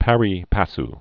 (părē păs, părī, pärē)